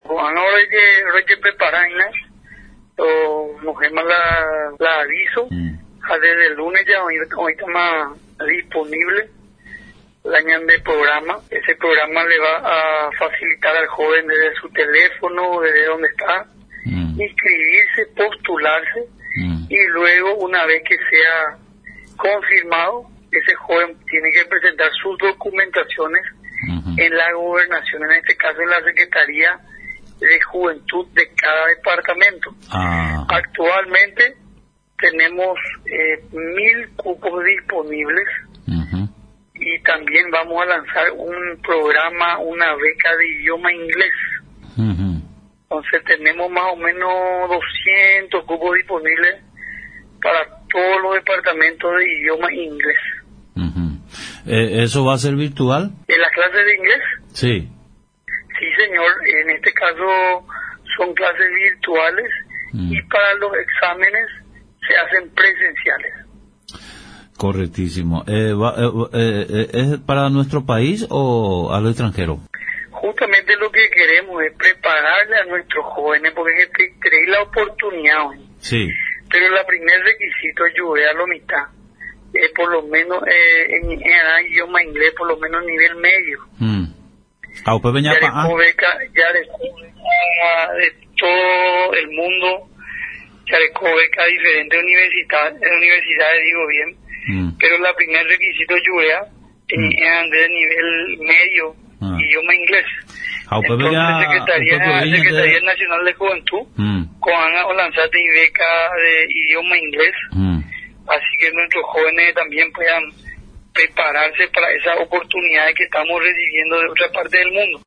El ministro de la Secretaría Nacional de la Juventud, Felipe Salomón, comentó a través de Radio Nacional San Pedro, de las principales inversiones que viene realizando la cartera a su cargo en nuestro país, llevando beneficios a todos los jóvenes del territorio nacional y principalmente de la zona norte del país, al respecto el titular de la SNJ informó a los interesados sobre la habilitación del formulario de solicitud y renovación de becas para este 2021.